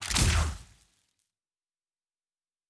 Sci-Fi Sounds / Weapons
Weapon 09 Shoot 3.wav